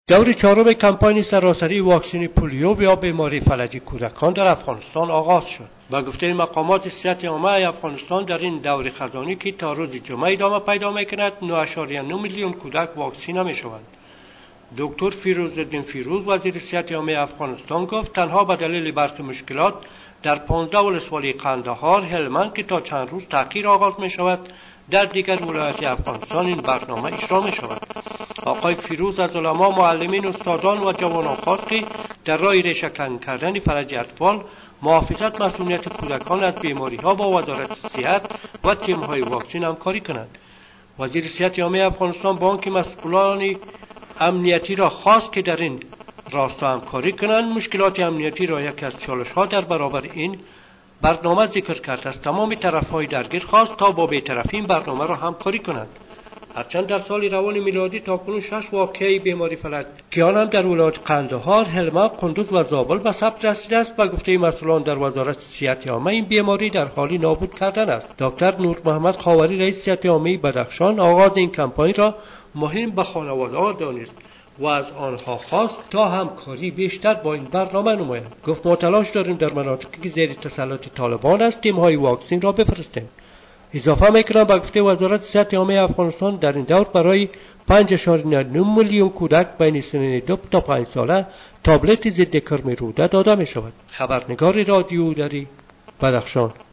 گزارش همکارمان